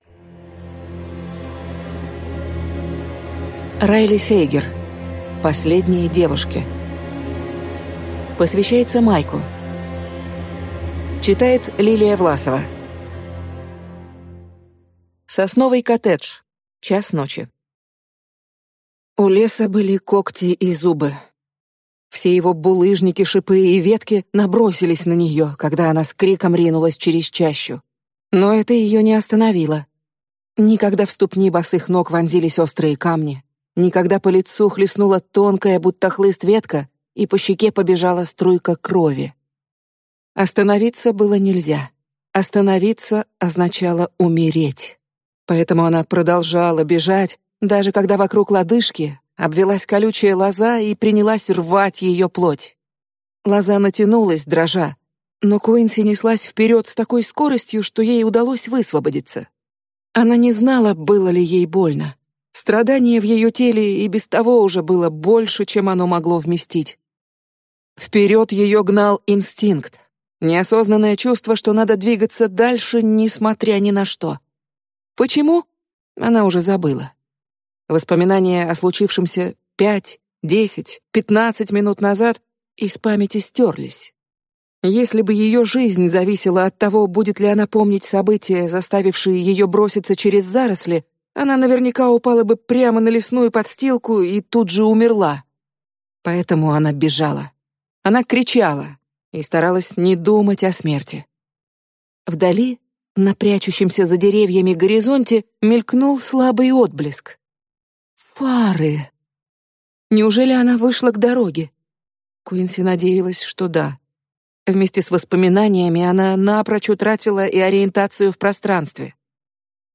Аудиокнига Последние Девушки | Библиотека аудиокниг